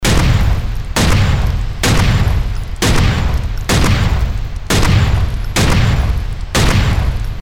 دانلود آهنگ تفنگ 8 از افکت صوتی اشیاء
دانلود صدای تفنگ 8 از ساعد نیوز با لینک مستقیم و کیفیت بالا
جلوه های صوتی